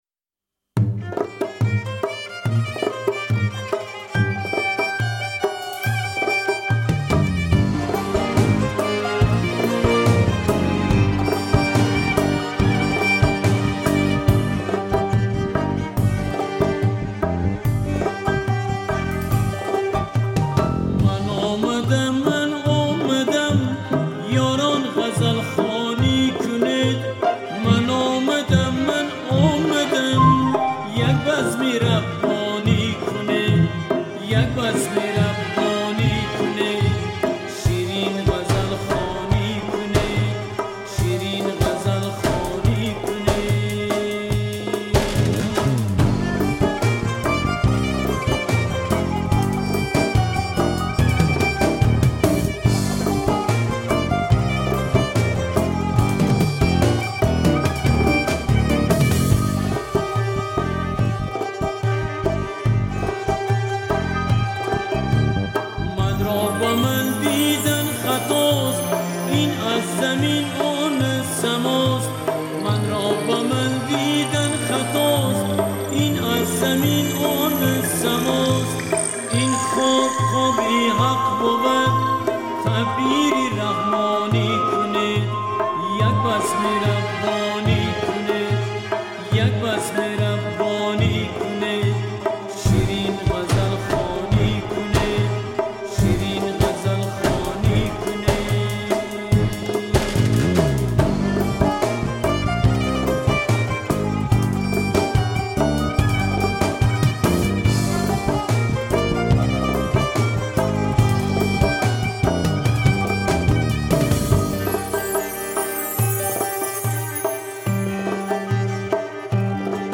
мусиқӣ